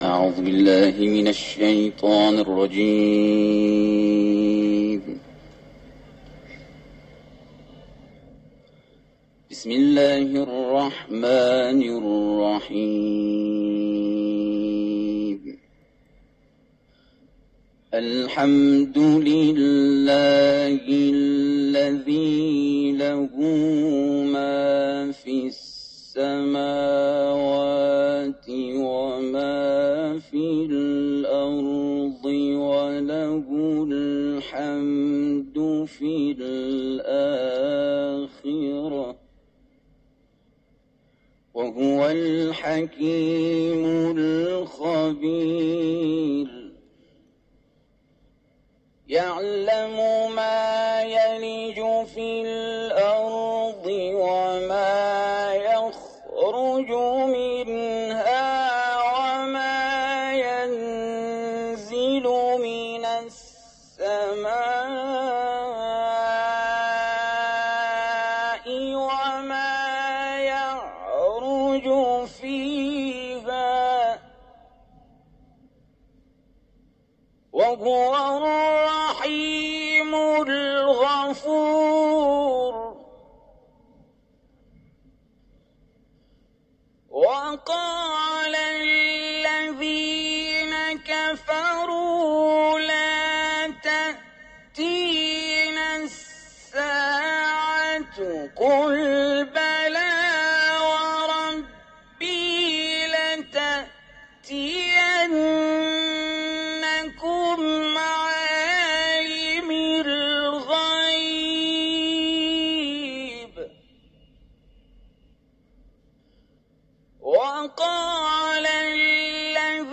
Tilawa ya Qur’ani Tukufu
Klipu ya sauti ya qiraa